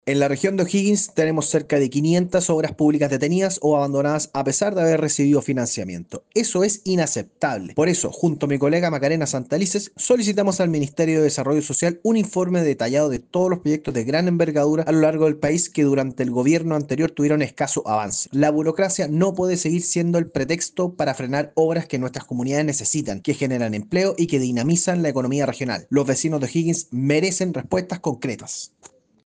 El diputado Cristoffanini sostuvo que es importante tener en conocimiento el desglose de estas obras, alertando que si en la capital nacional hay demoras, en las regiones esta situación podría ser mucho peor, escuchemos: